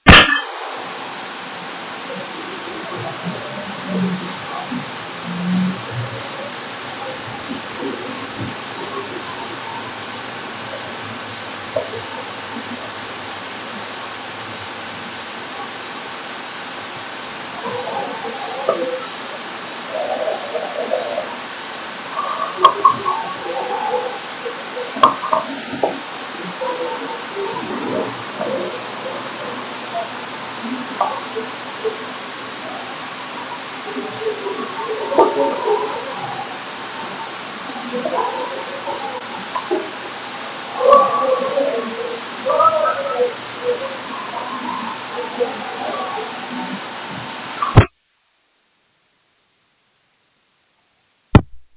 In this recording below I would not have heard anything as it all happens in sub vocal volumes until you use a parabolic microphone to locate the source of the sound.